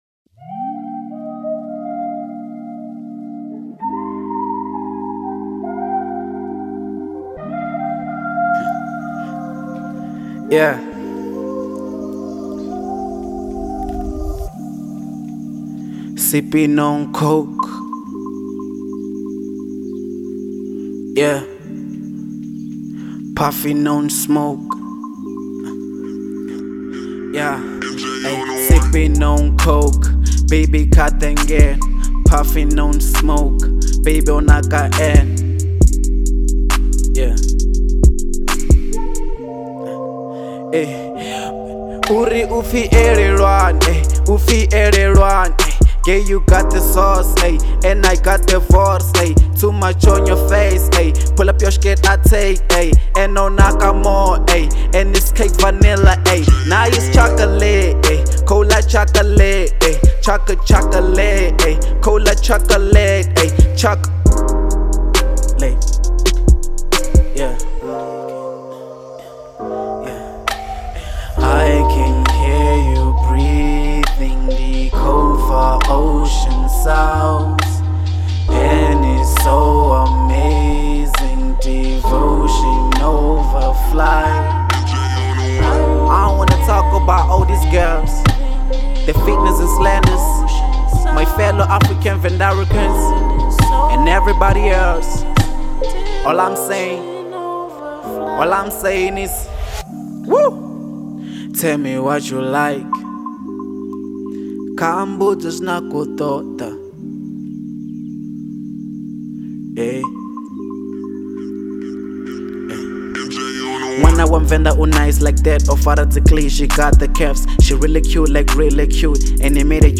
03:05 Genre : Venrap Size